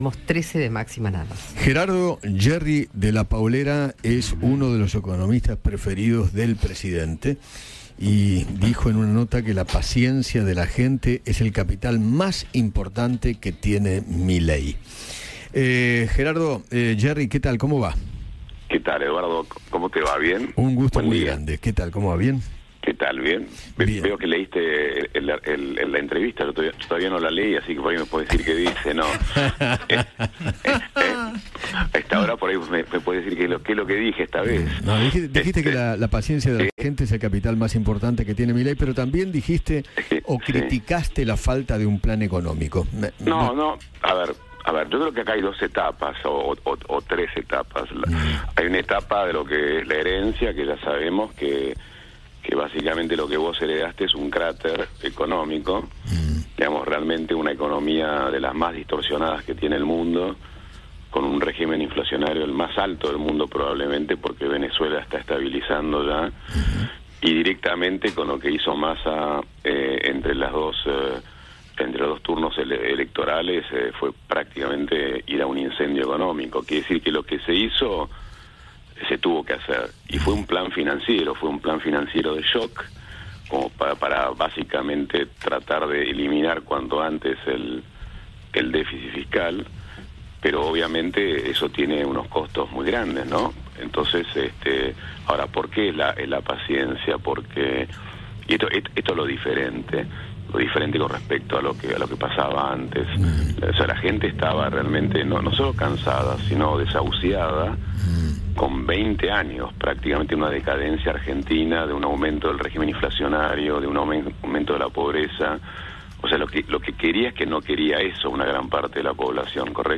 Eduardo Feinmann conversó con el economista Gerardo Della Paolera sobre la situación económica actual de Argentina y criticó la falta de un plan económico integral por parte del gobierno de Javier Milei.